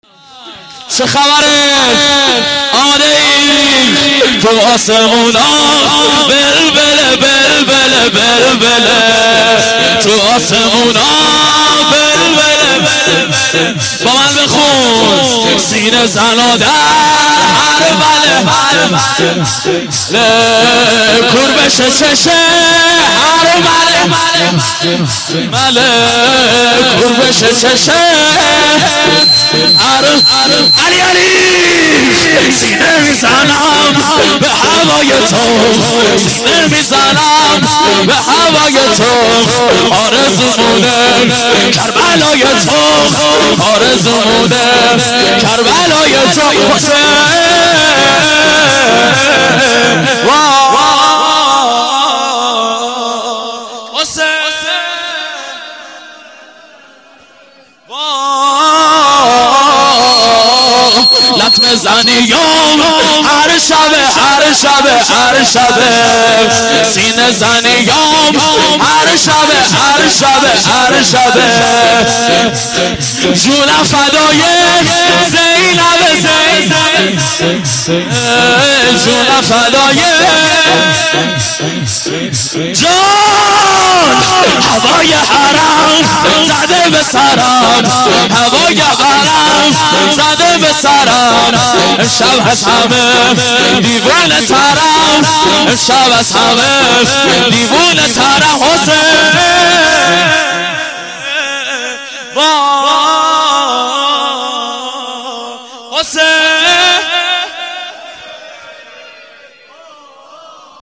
شور - تو آسمونا وله وله - مداح